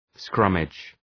Προφορά
{‘skrʌmıdʒ}